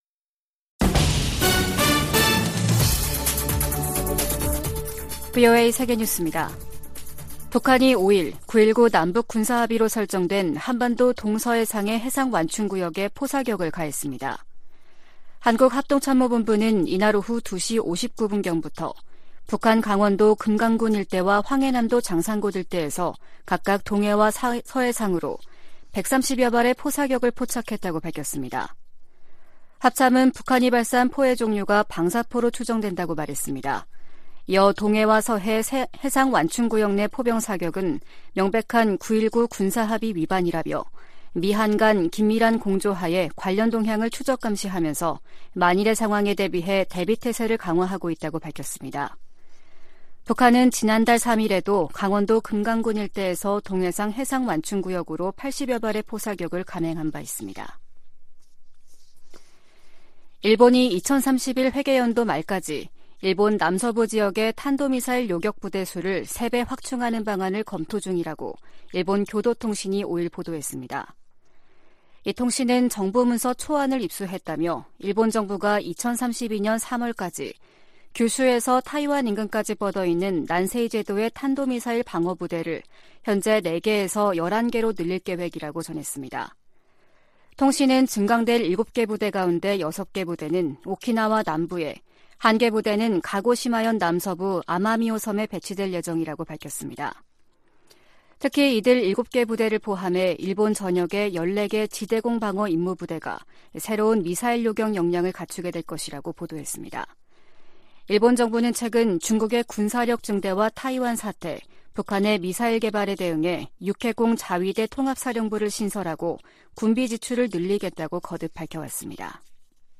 VOA 한국어 아침 뉴스 프로그램 '워싱턴 뉴스 광장' 2022년 12월 6일 방송입니다. 북한이 오늘 동해와 서해상으로 남북 군사합의를 위반하는 무더기 포 사격을 가했습니다. 백악관 고위 당국자가 북한의 핵 기술과 탄도미사일 역량 발전이 누구의 이익에도 부합하지 않는다면서 중국과 러시아에 적극적인 제재 동참을 촉구했습니다.